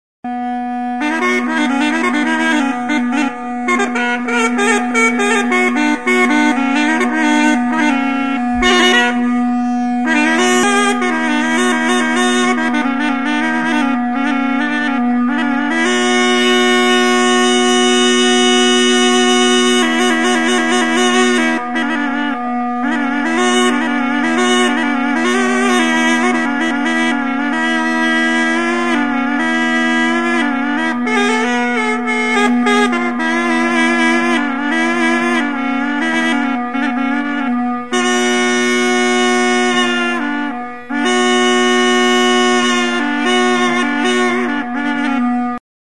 Instrumentos de músicaARGHUL; ARGUL; ZAMMARA
Aerófonos -> Lengüetas -> Simple (clarinete)
Klarinete bikoitza da.